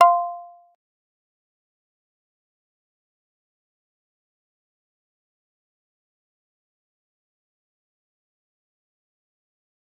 G_Kalimba-F5-pp.wav